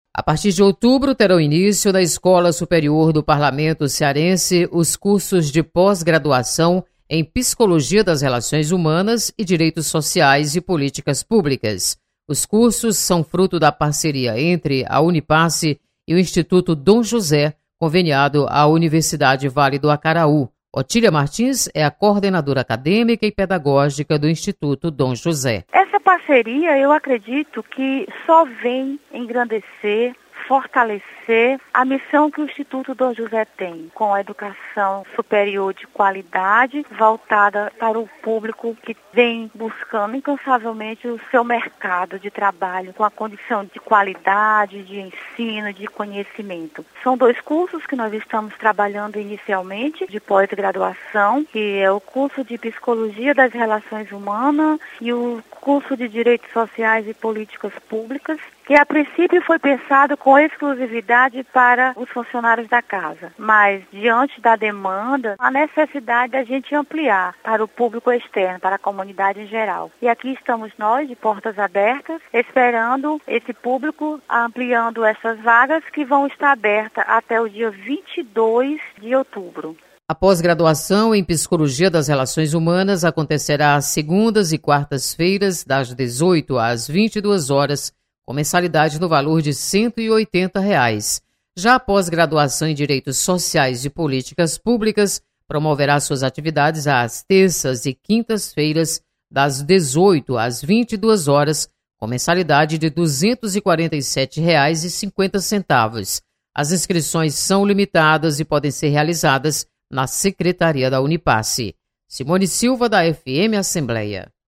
Publicado em Notícias